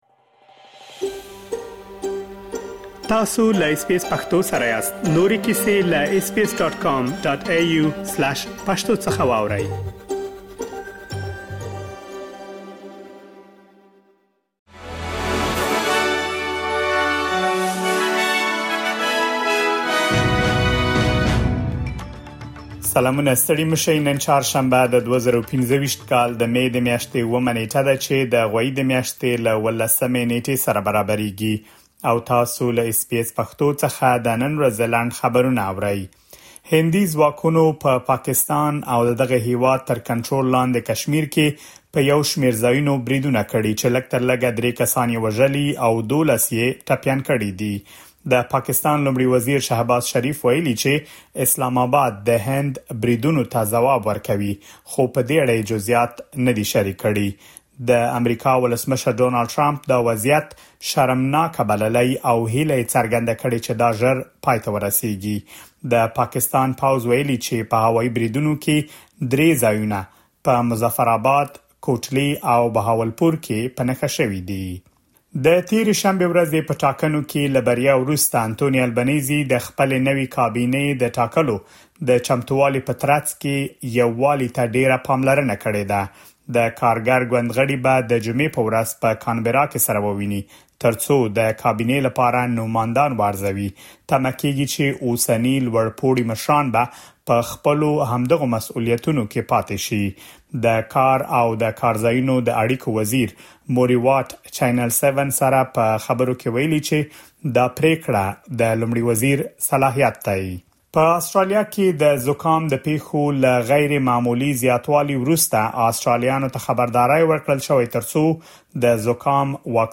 د اس بي اس پښتو د نن ورځې لنډ خبرونه | ۷ مې ۲۰۲۵